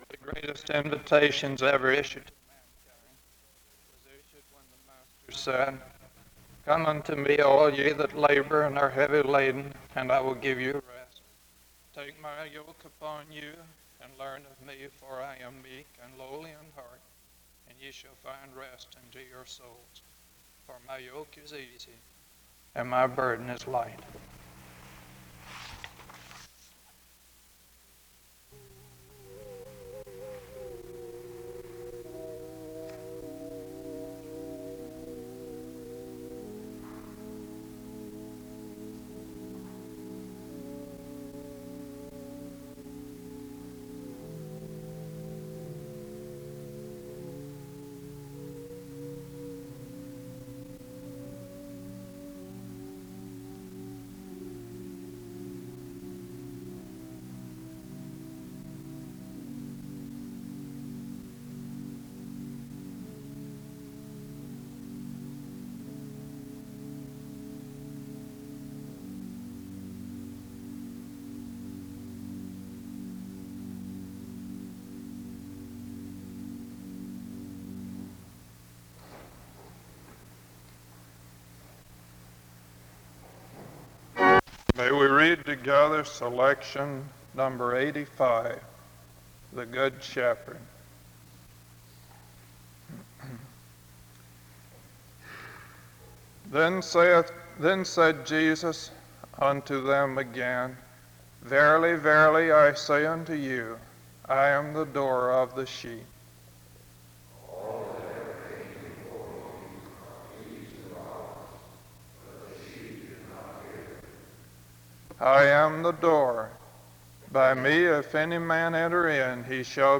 The service begins with an opening scripture reading and music from 0:00-1:18. A responsive reading takes place from 1:24-3:53. A prayer is offered from 3:54-6:04. An introduction to the speaker is given from 6:07-6:53.